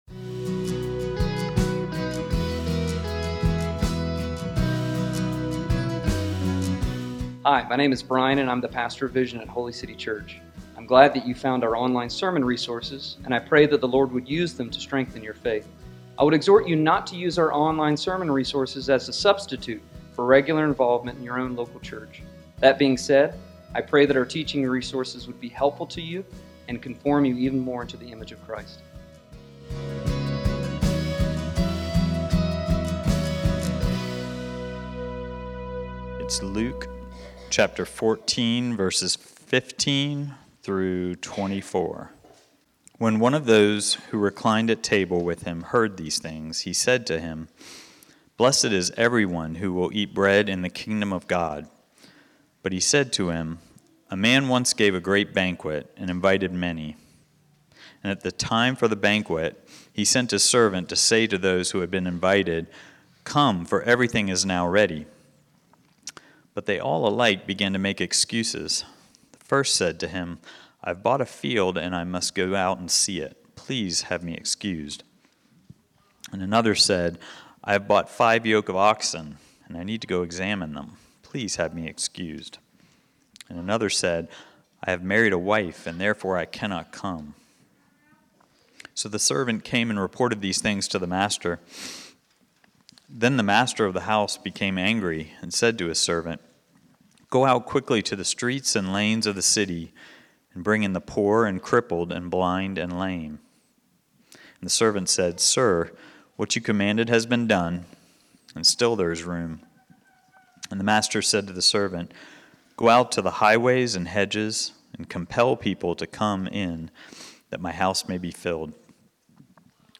Individual Sermons